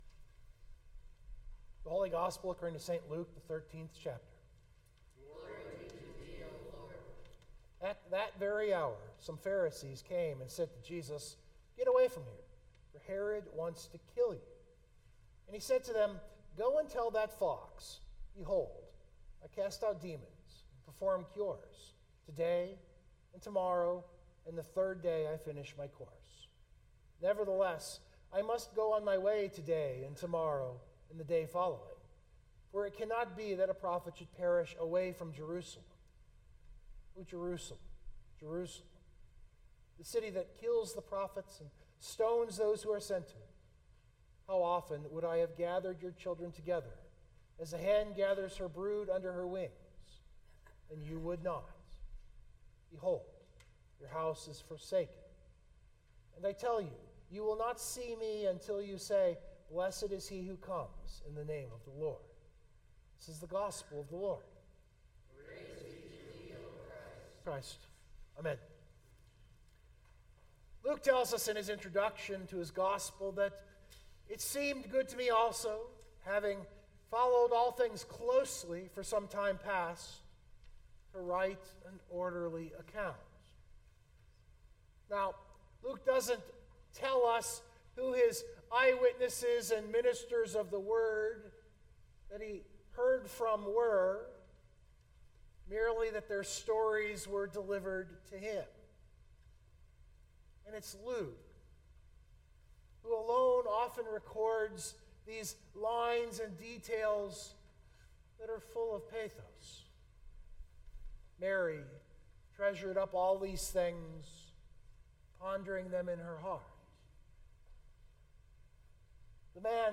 031625 Sermon Download Biblical Text: Luke 13:31-35 Luke to me is a master of phrases that drip with pathos.